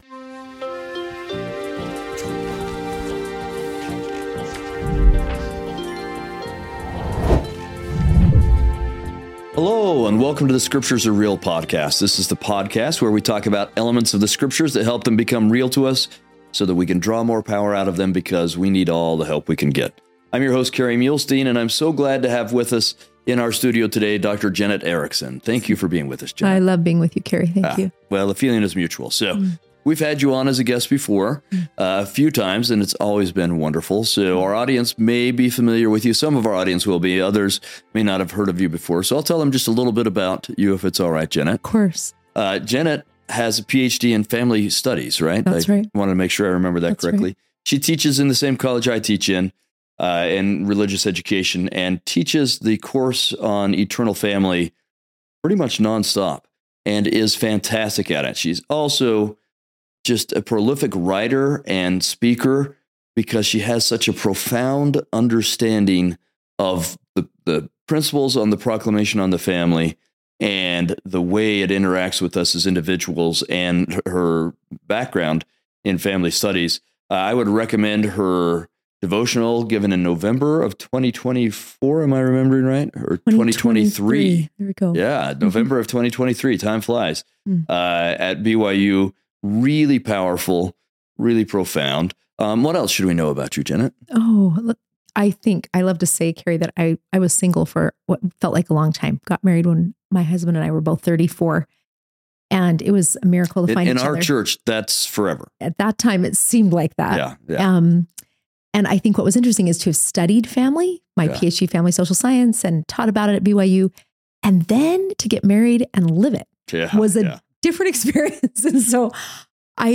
We interview both experts (people with language, archaeological, historical backgrounds, etc.), and lay folks, and explore times when the scriptures became real to them. This is done from the viewpoint of members of the Church of Jesus Christ of Latter-day Saints.